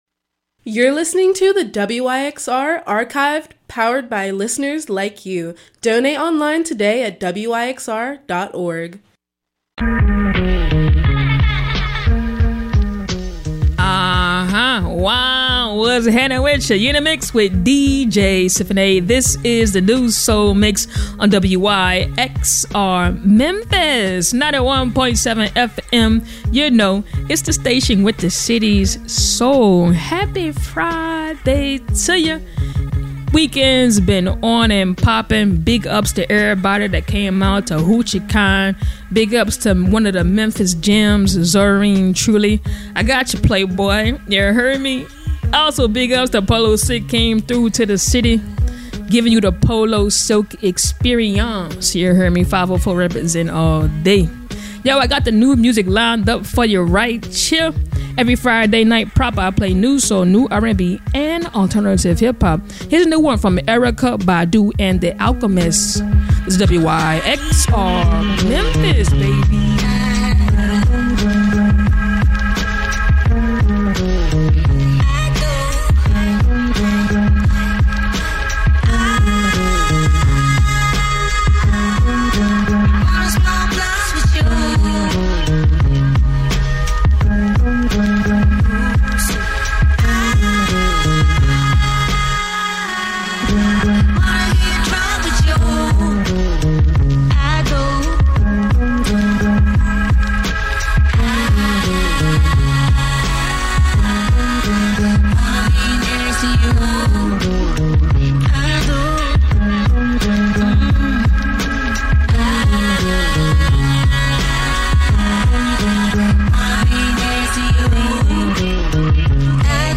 Neo Soul Hip Hop